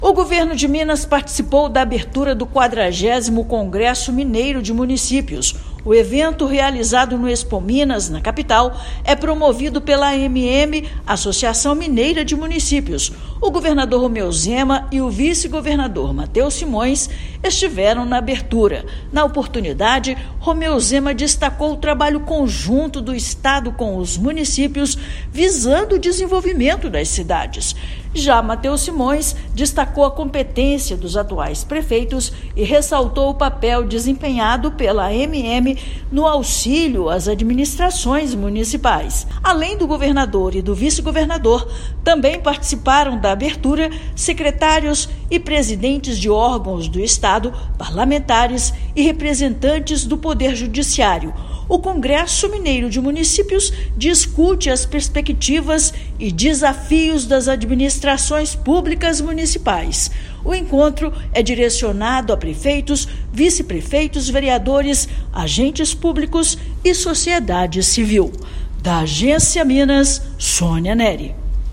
Evento tradicional da Associação Mineira de Municípios deve reunir cerca de 10 mil pessoas para discutir os desafios das prefeituras do estado. Ouça matéria de rádio.